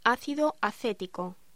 Locución: Ácido acético
voz